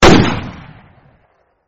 Tải âm thanh tiếng súng Colt 45 mp3 | Download Miễn phí